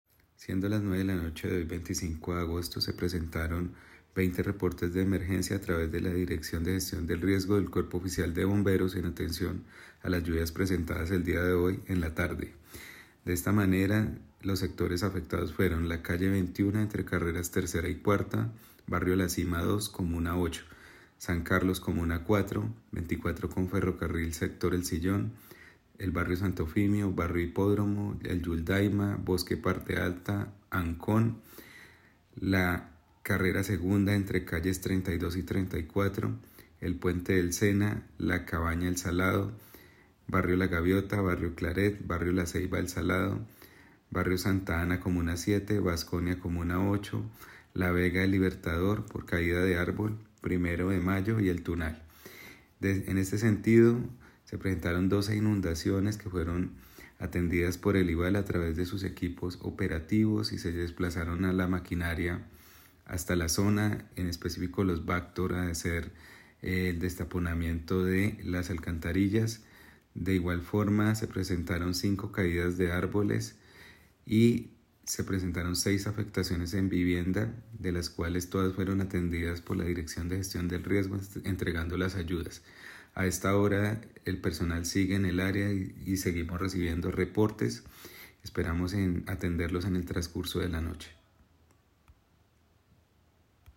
Humberto-Leal-secretario-E-Ambiente-y-Gestion-del-Riesgo-.mp3